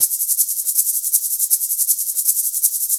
Shaker 01.wav